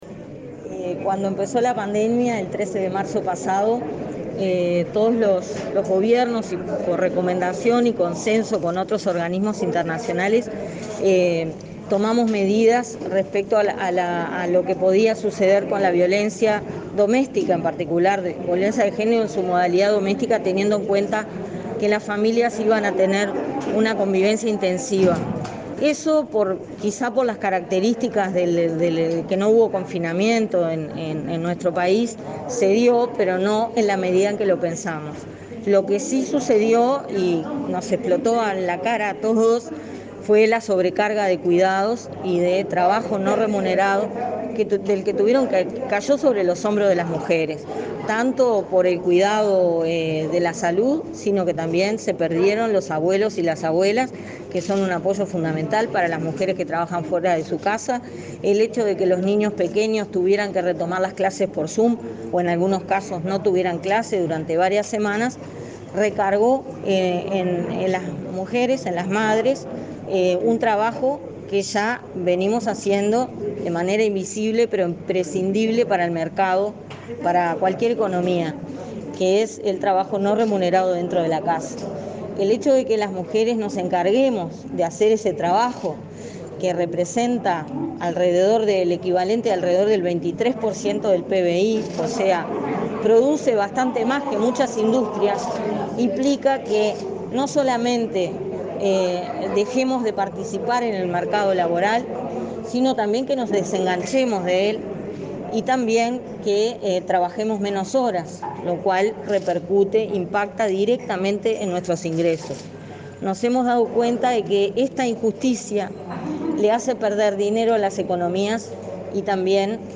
Acto oficial del Gobierno por el Día Internacional de la Mujer